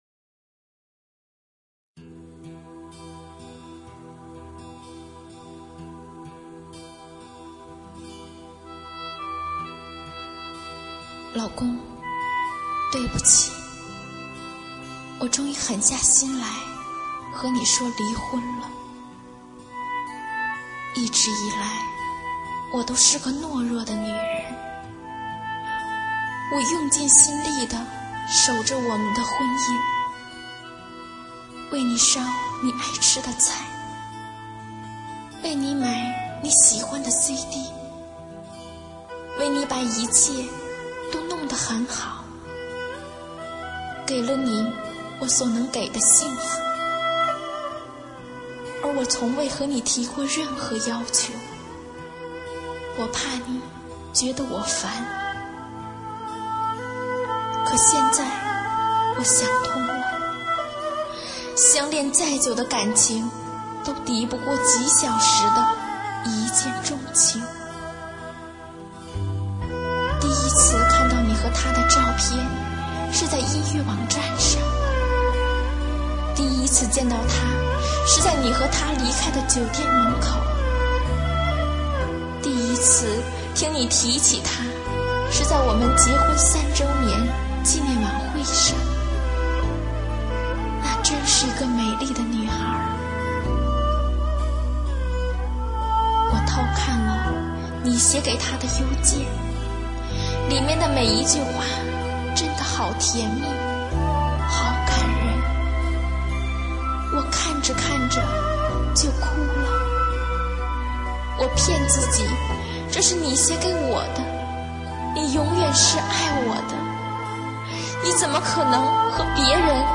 [27/9/2009]配乐述说--不幸的婚姻 激动社区，陪你一起慢慢变老！